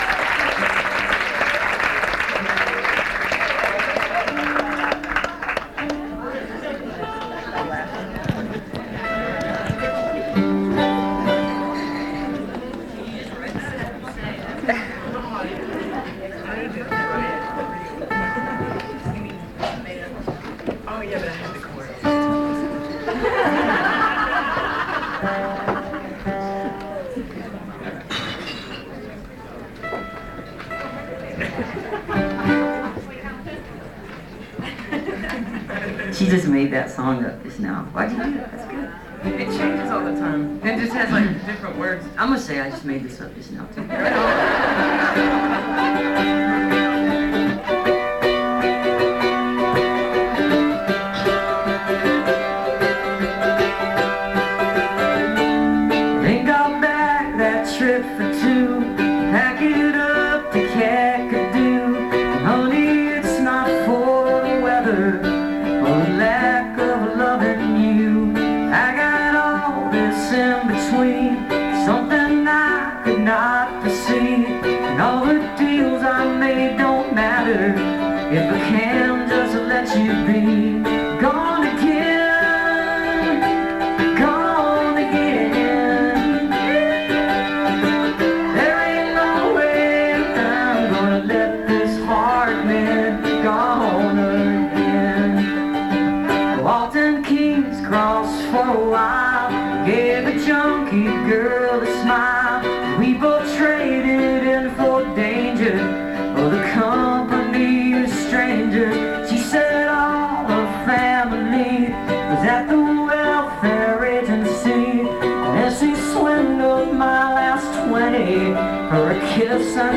(songwriters in the round)